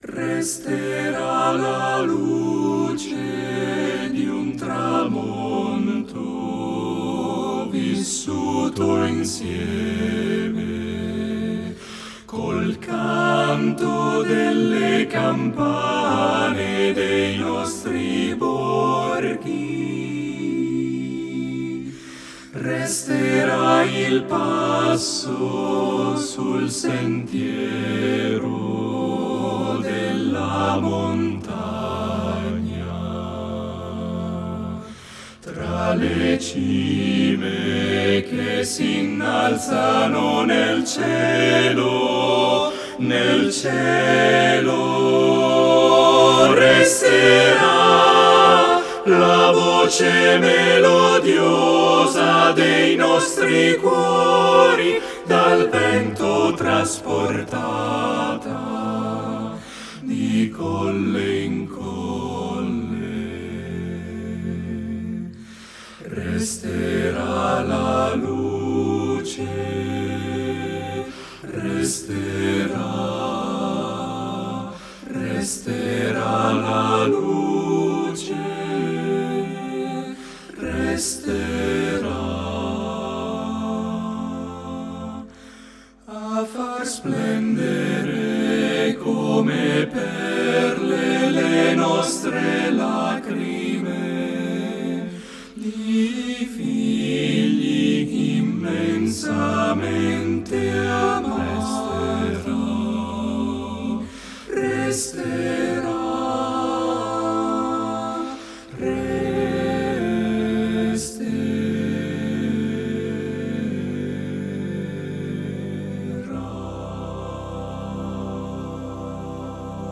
Voicing: TTBB* a cappella